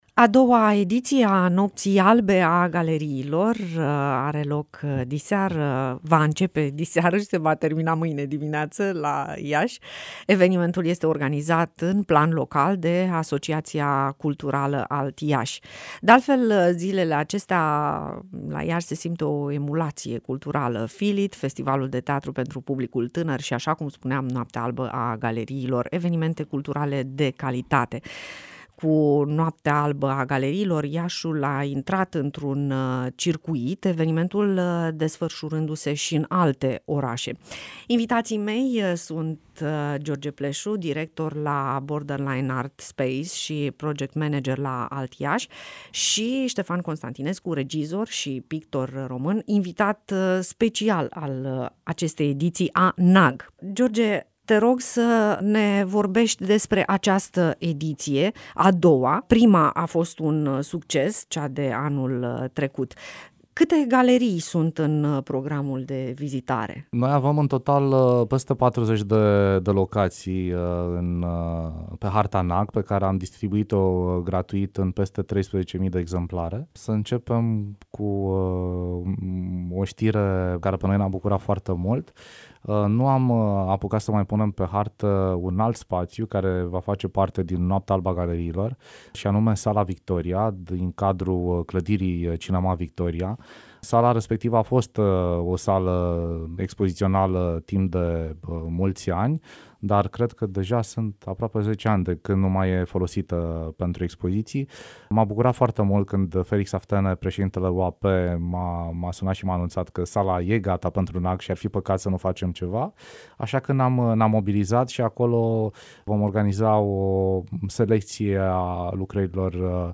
Noapte albă la Iași... a galeriilor - INTERVIU